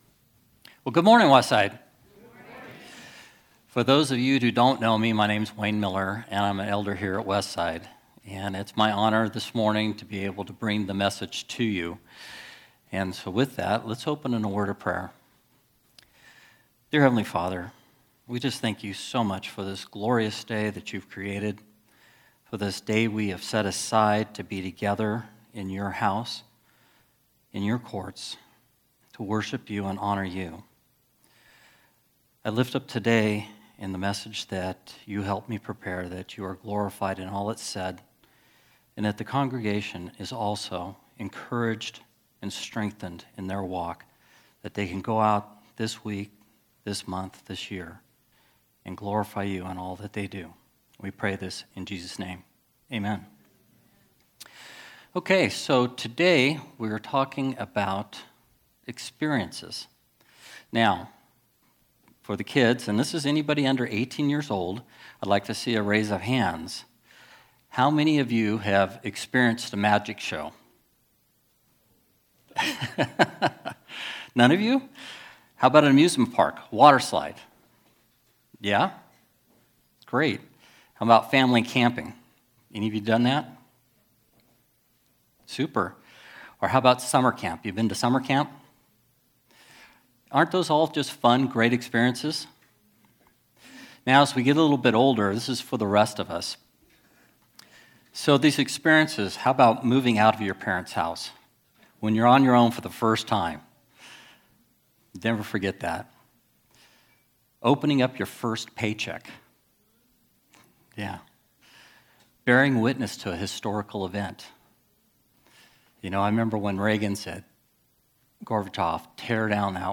Special Sermon Passage